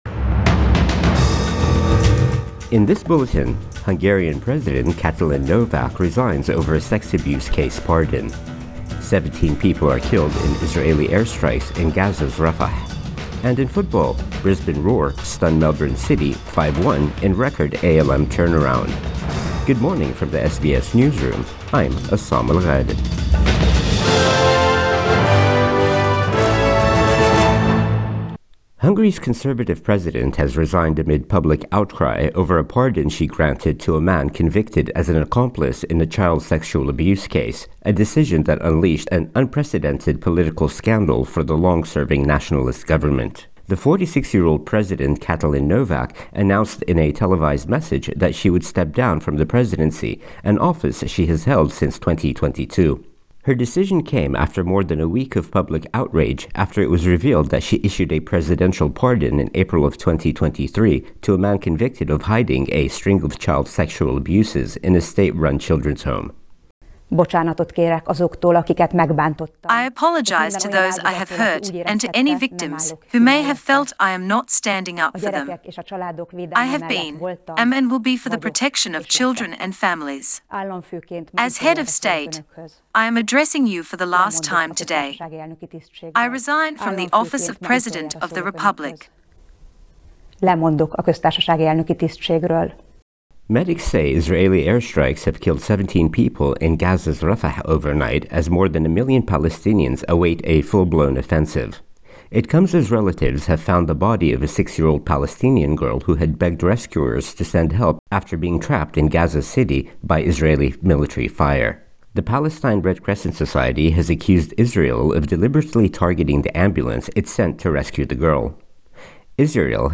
Morning News Bulletin 11 February 2024